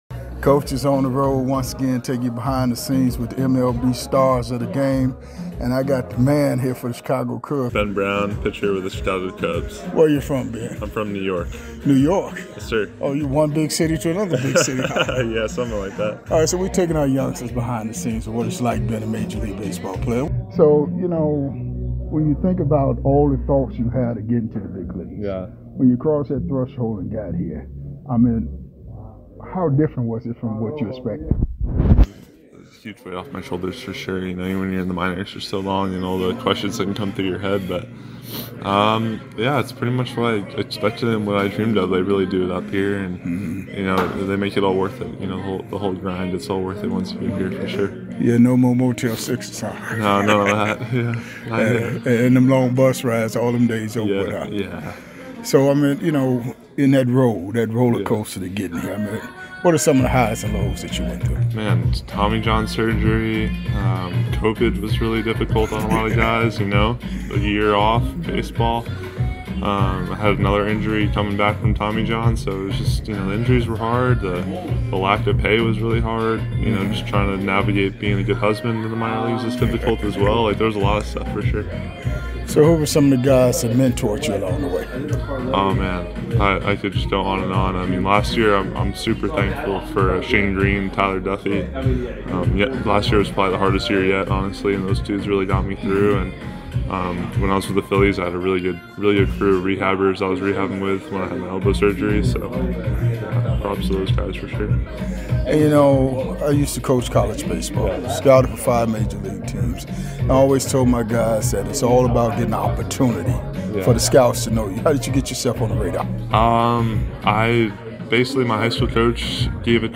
⚾ MLB Classic Interviews – Coaches Corner